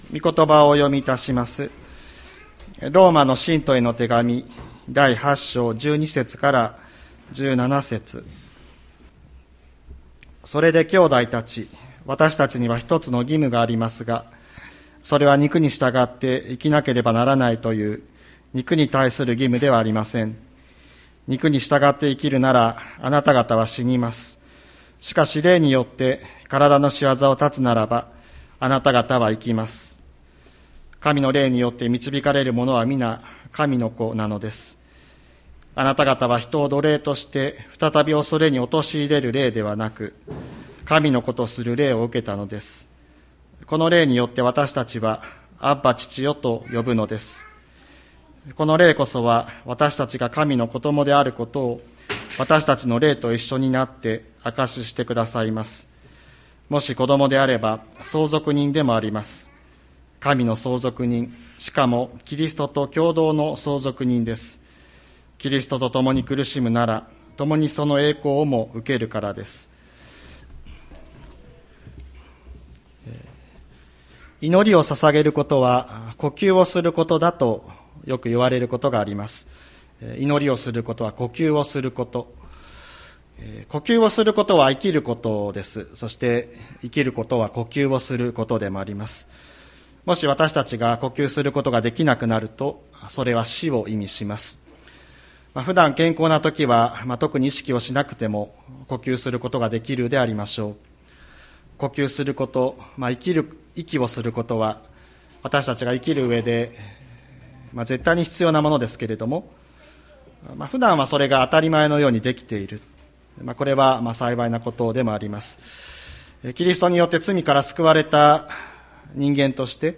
2023年02月26日朝の礼拝「神を父と呼ぼう」吹田市千里山のキリスト教会